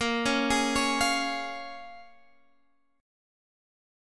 A#m7 chord